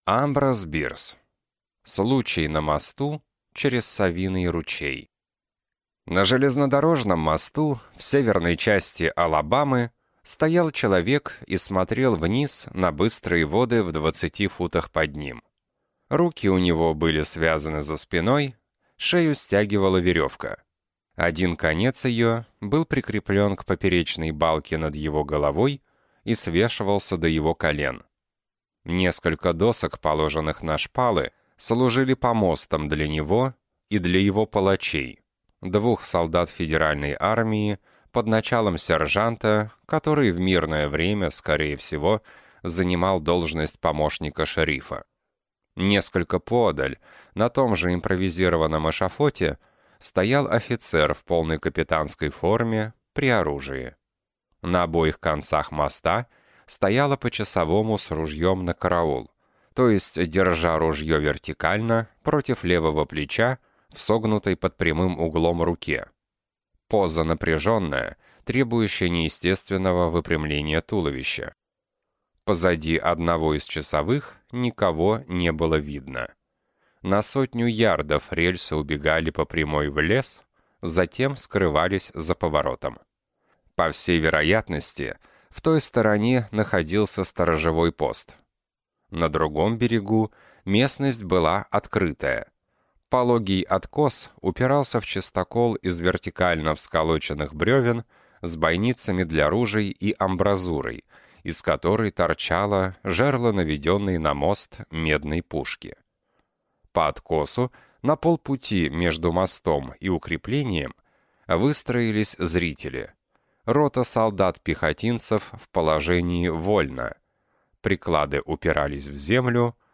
Аудиокнига Случай на мосту через Совиный ручей | Библиотека аудиокниг